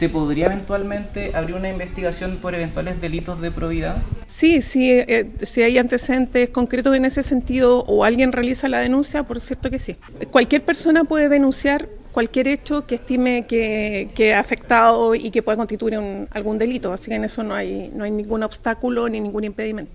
También se refirió a estos hechos la fiscal regional de Los Lagos, Carmen Gloria Wittwer, quien señaló que de encontrarse antecedentes constitutivos de delitos podría iniciarse una indagatoria.